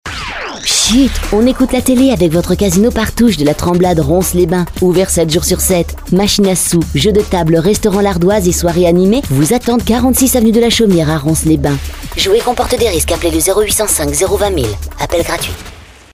et voici le spot de notre partenaire